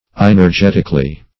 inergetically - definition of inergetically - synonyms, pronunciation, spelling from Free Dictionary
inergetically - definition of inergetically - synonyms, pronunciation, spelling from Free Dictionary Search Result for " inergetically" : The Collaborative International Dictionary of English v.0.48: Inergetically \In`er*get"ic*al*ly\, adv.